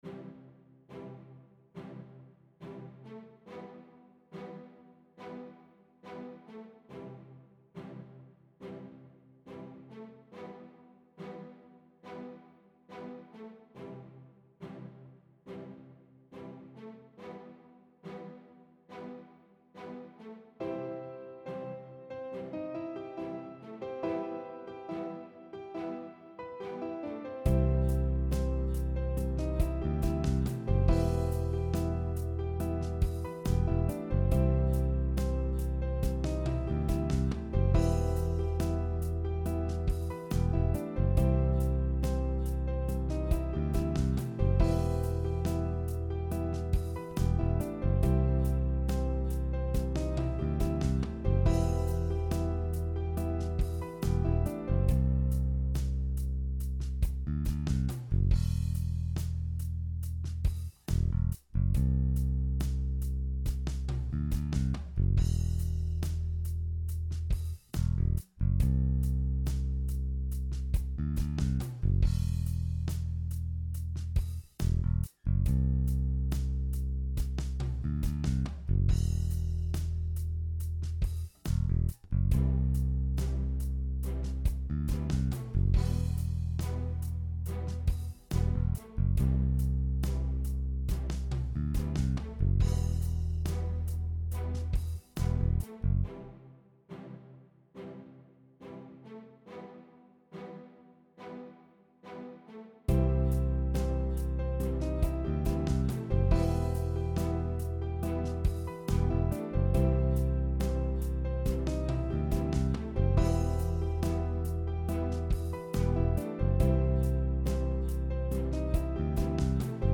Home > Music > Rnb > Bright > Smooth > Medium